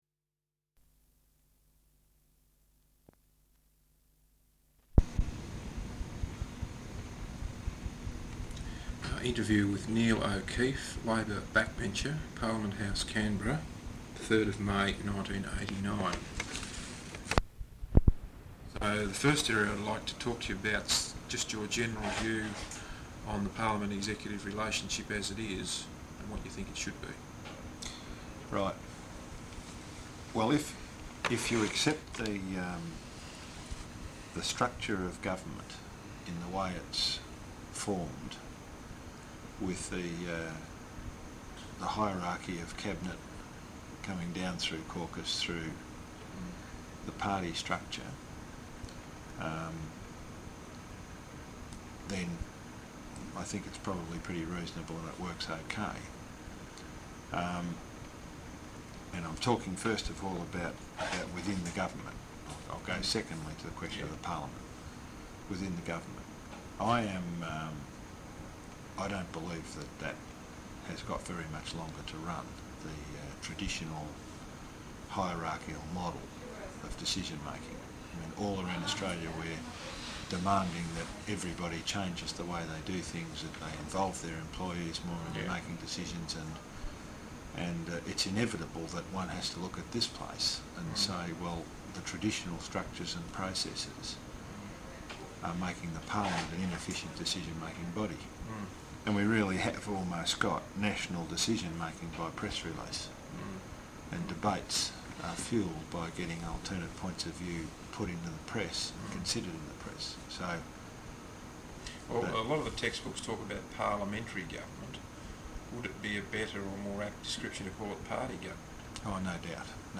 Interview with Neil O’Keefe, Labor Backbencher, Parliament House, Canberra 3rd May 1989.